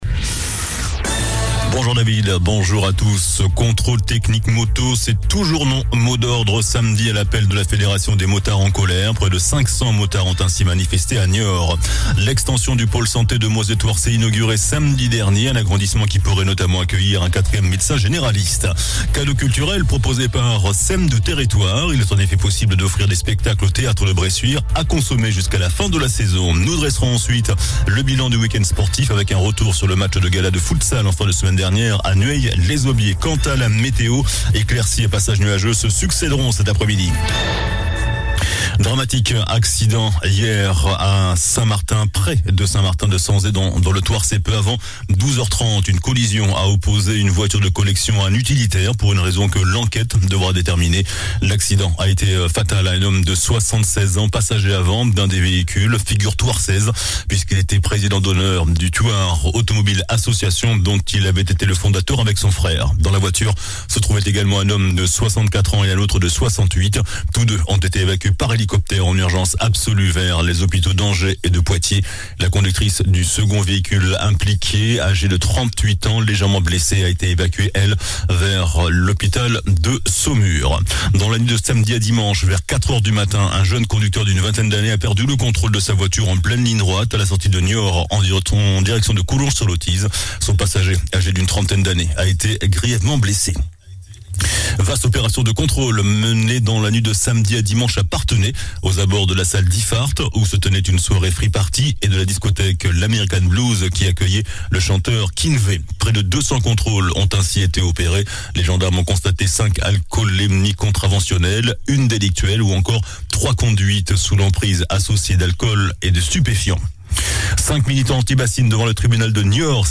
JOURNAL DU LUNDI 28 NOVEMBRE ( MIDI )